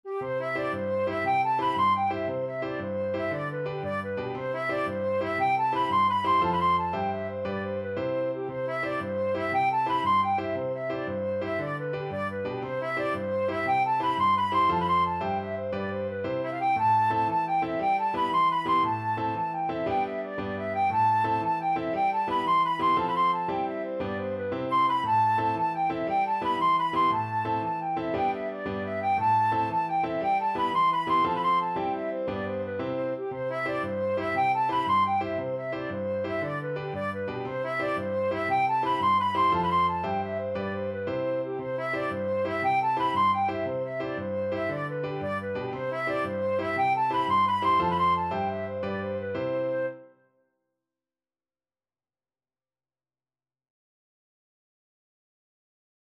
Flute
Traditional Music of unknown author.
C major (Sounding Pitch) (View more C major Music for Flute )
6/8 (View more 6/8 Music)
With energy .=c.116
Irish